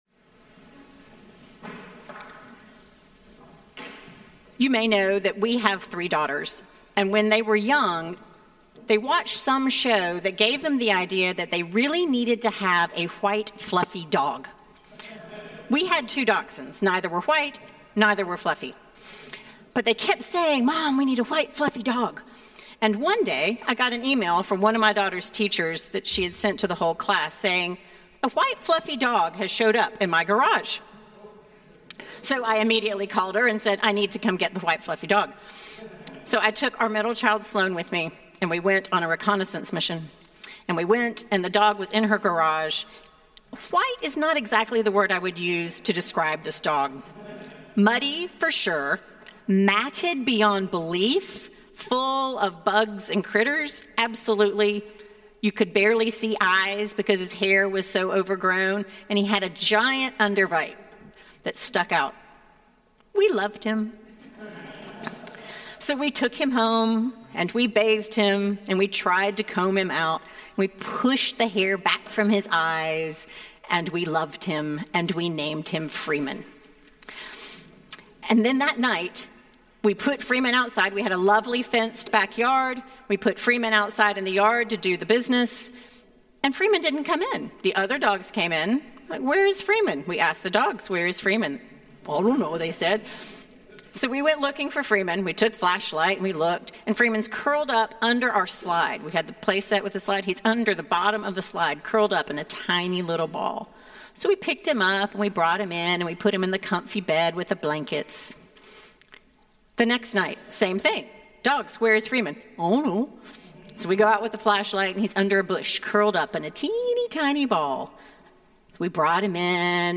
Sermons from St. Paul’s Episcopal Church, Cleveland Heights, Ohio